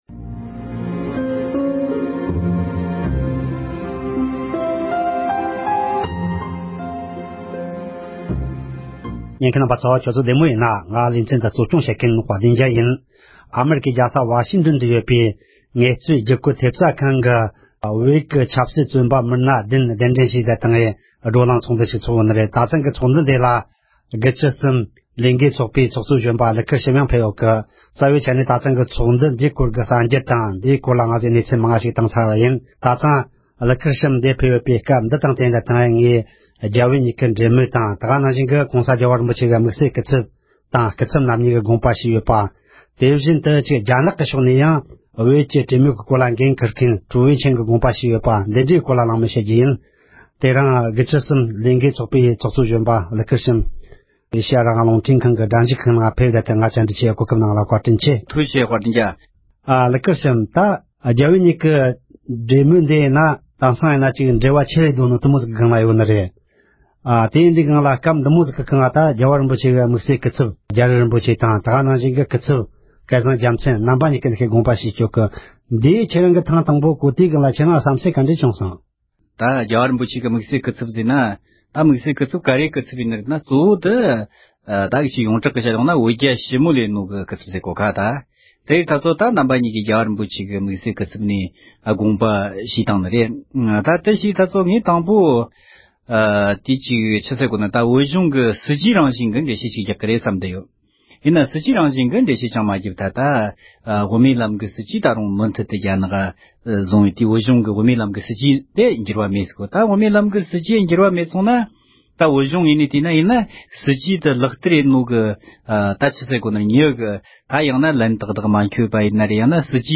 བོད་རྒྱ་འབྲེལ་མོལ་དང་སྐུ་ཚབ་དགོངས་ཞུ་ཐད་གླེང་མོལ།